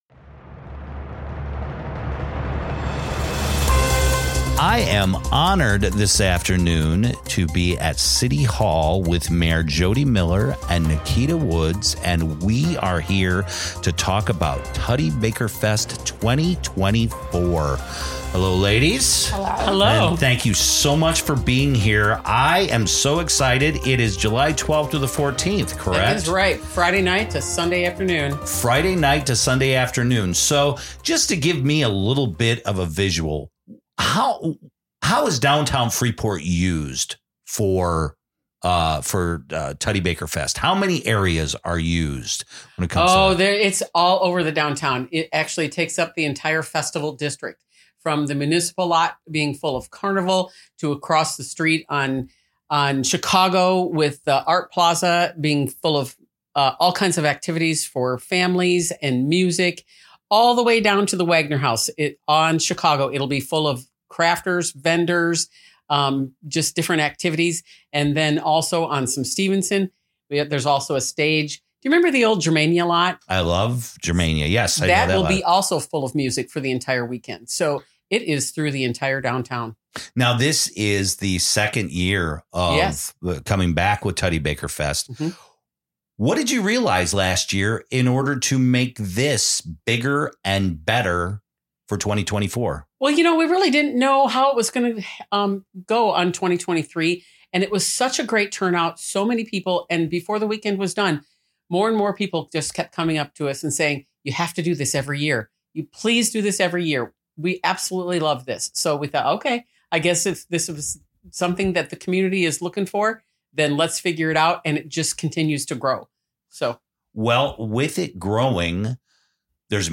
Freepod - Freepod Interviews: Tutty Baker Fest